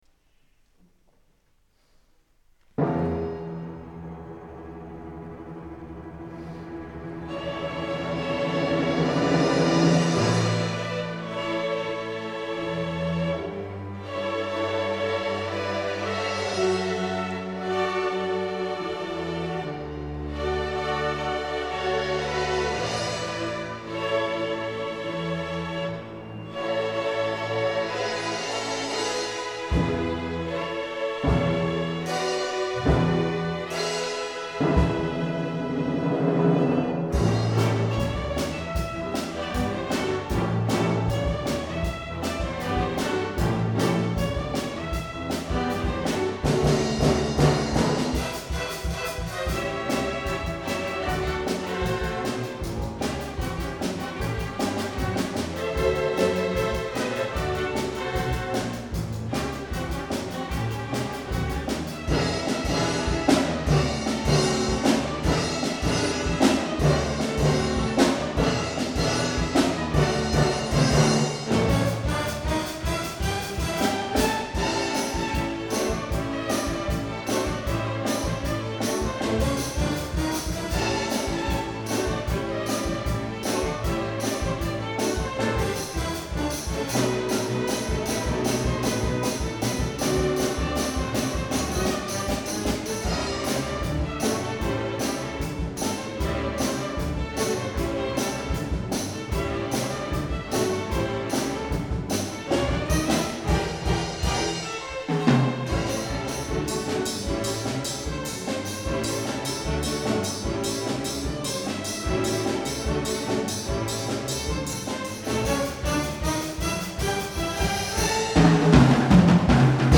第29回定期演奏会